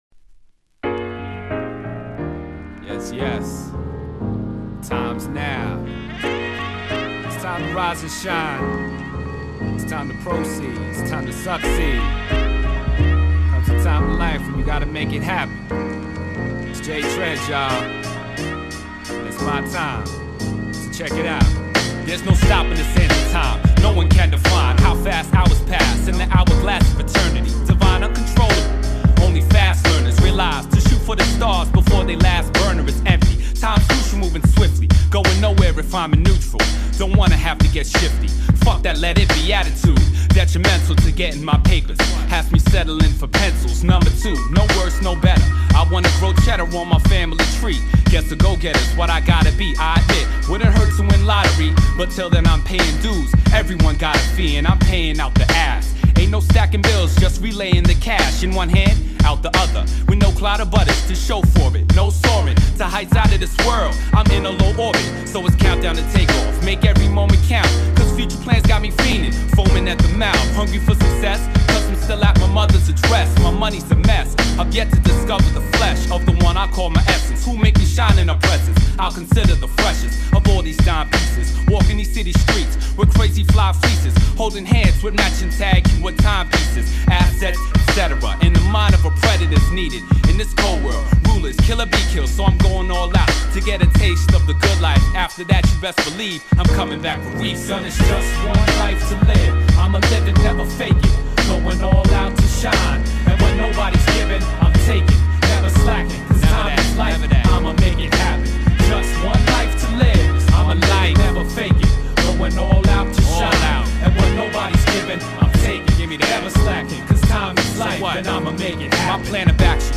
upbeat, positive and philosophical.